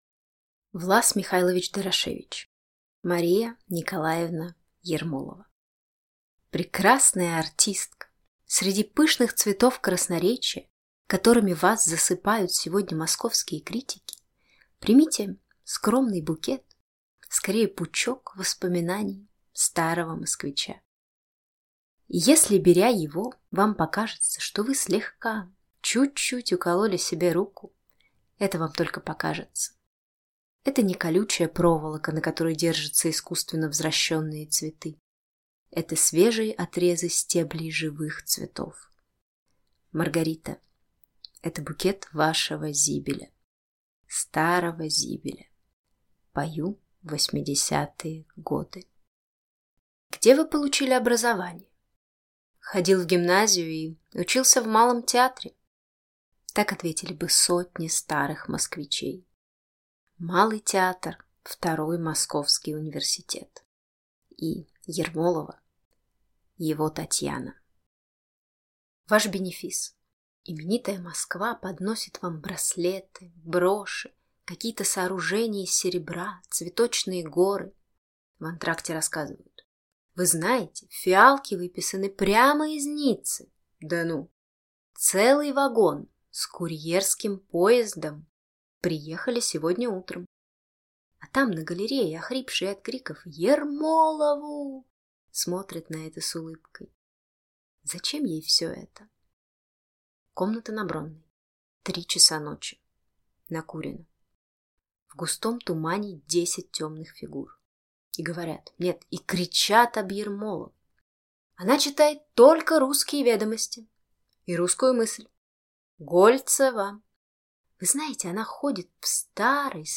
Aудиокнига М. Н. Ермолова